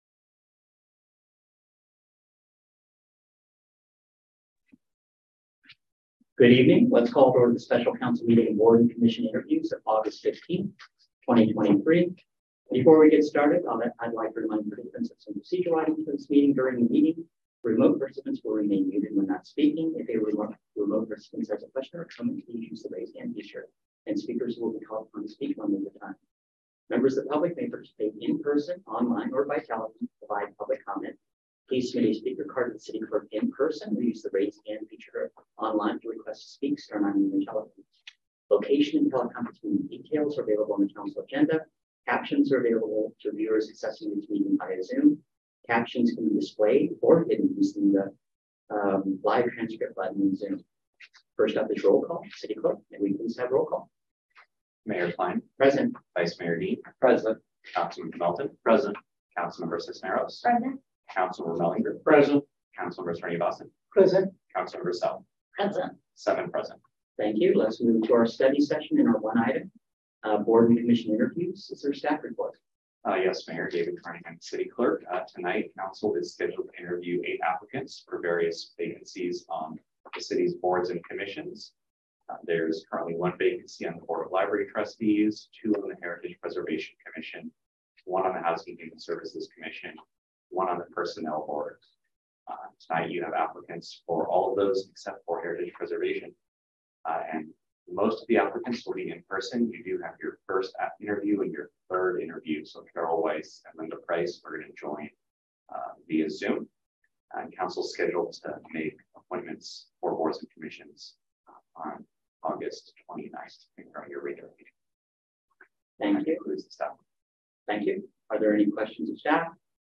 Special Meeting: Board and Commission Interviews - 6 PM - Aug 15, 2023